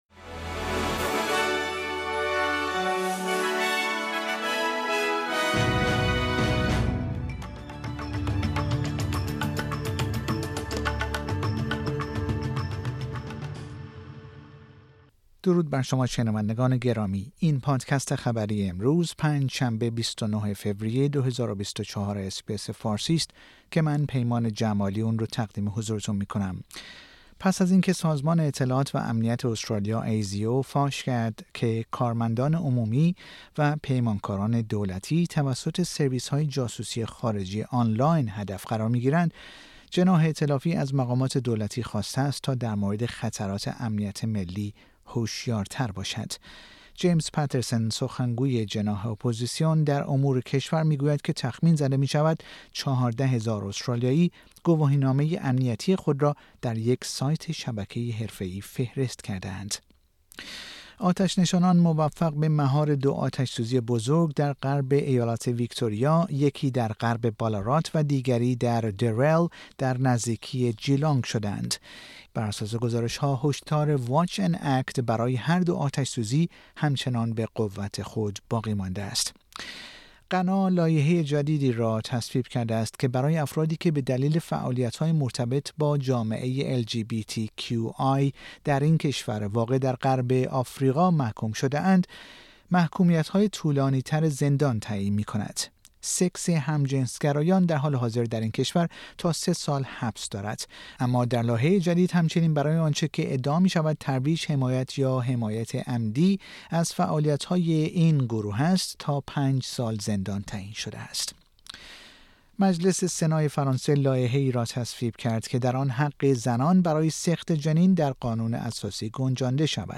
در این پادکست خبری مهمترین اخبار استرالیا و جهان در روز پنج شنبه ۲۹ فوریه ۲۰۲۴ ارائه شده است.